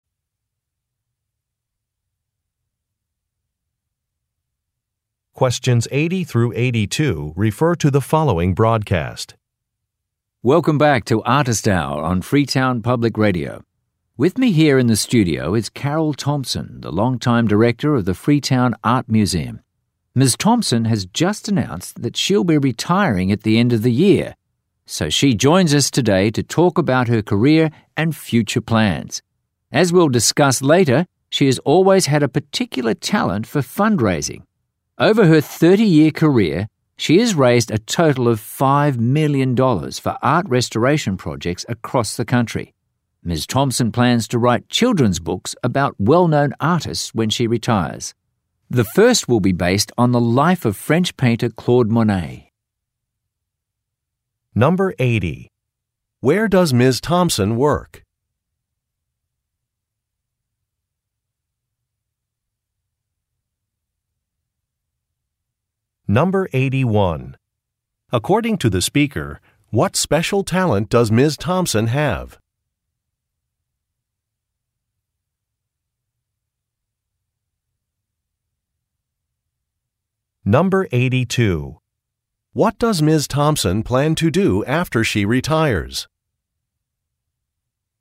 Question 80 - 82 refer to following talk: